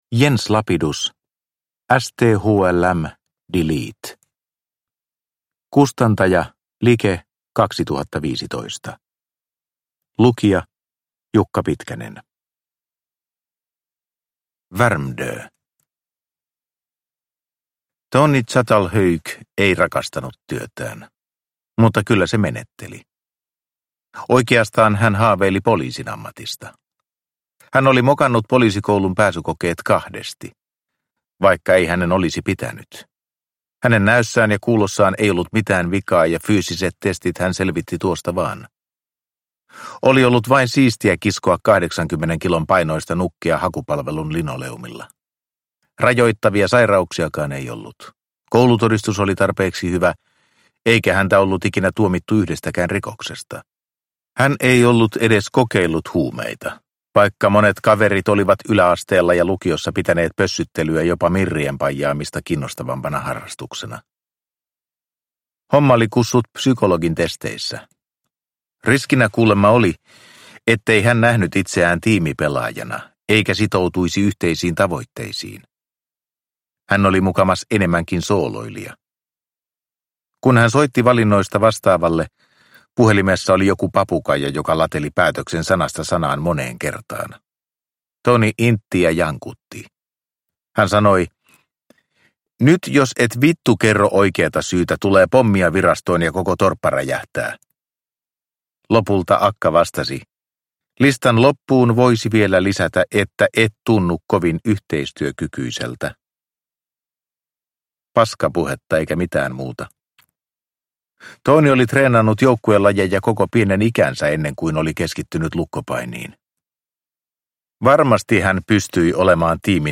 Sthlm delete – Ljudbok – Laddas ner